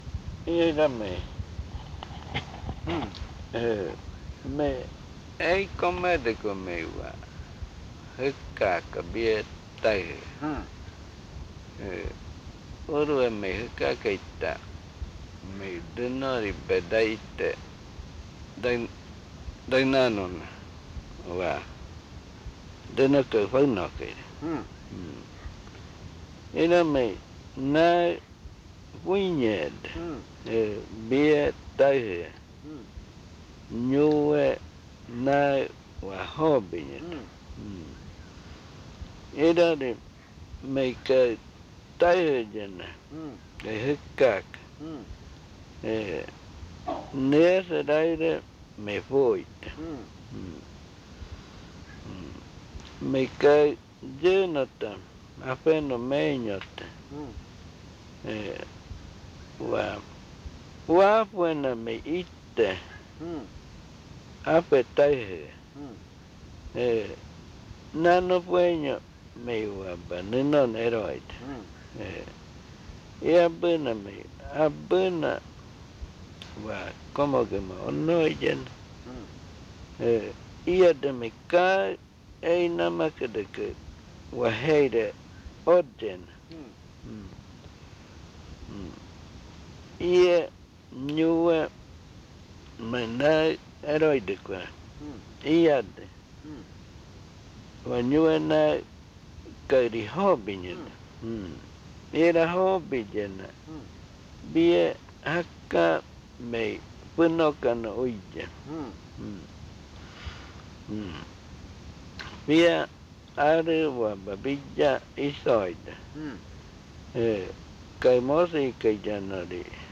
Diálogo